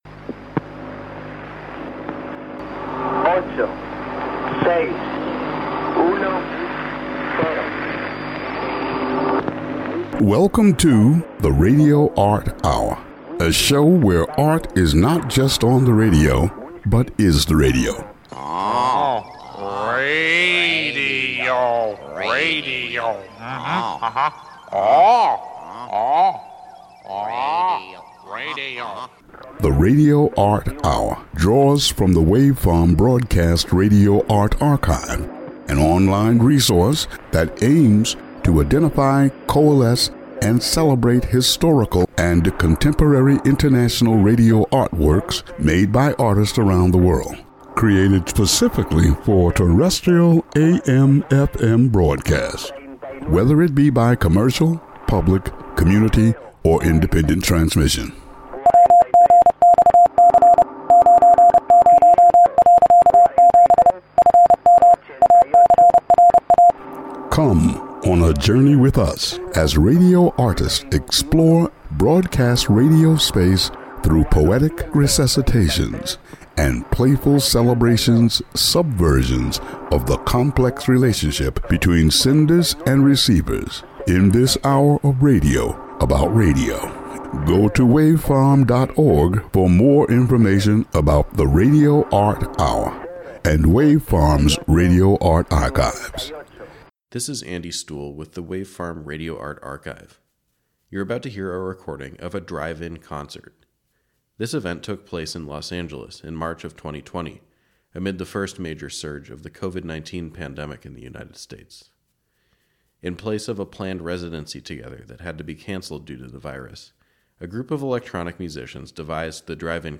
a group of local electronic musicians